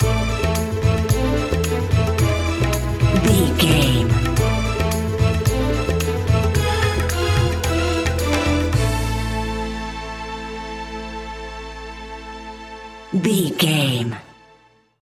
Aeolian/Minor
percussion